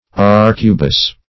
arcubus - definition of arcubus - synonyms, pronunciation, spelling from Free Dictionary Search Result for " arcubus" : The Collaborative International Dictionary of English v.0.48: Arcubus \Ar"cu*bus\, n. See Arquebus .